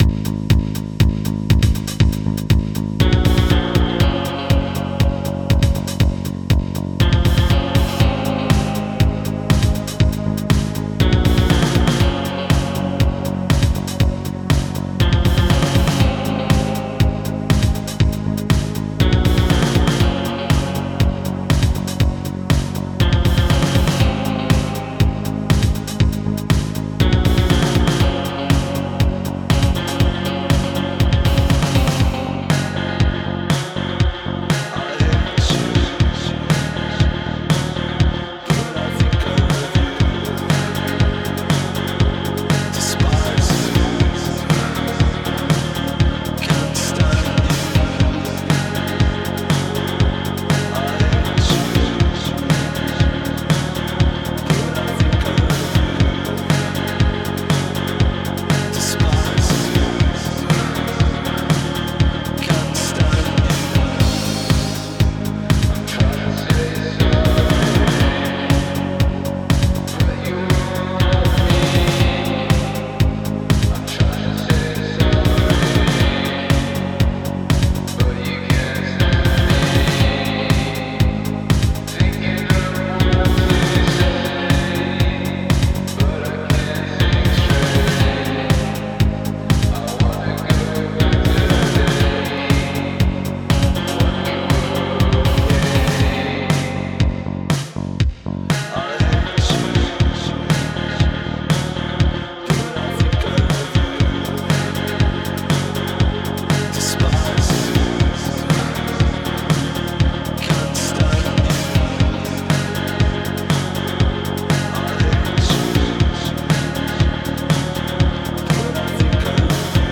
goth.mp3